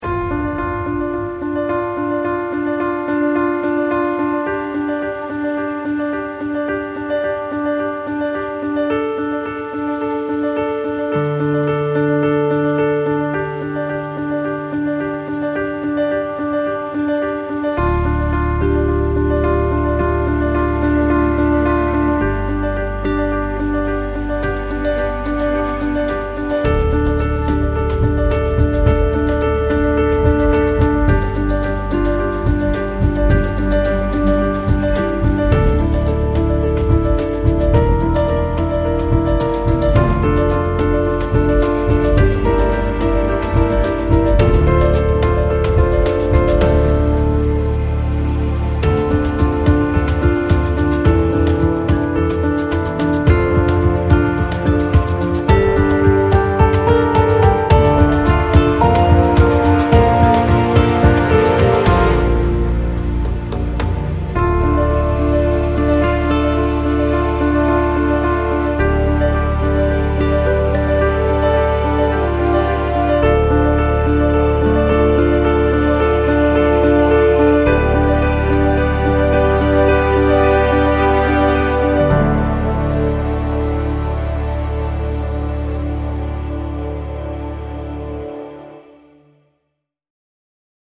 Largo [0-10] - - folk - guitare - neige - hiver - chaleureux